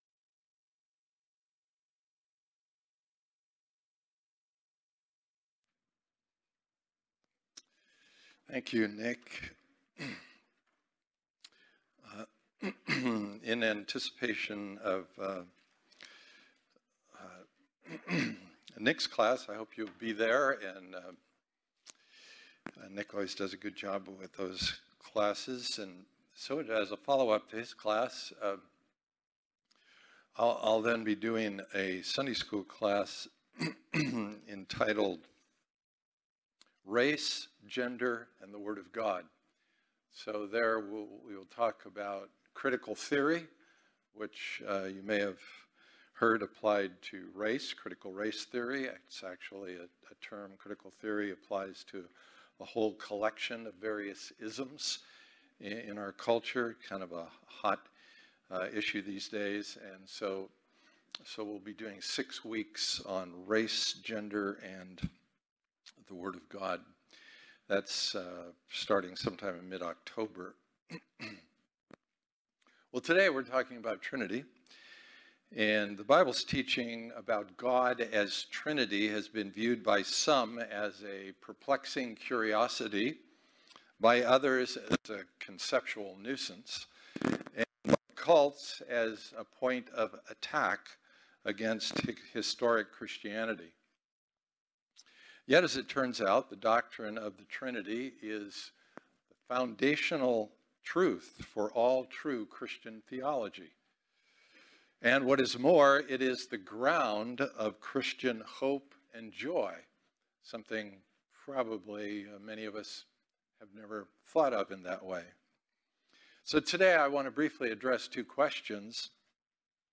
2021 Series: The Beauty of Paradox Type: Sermons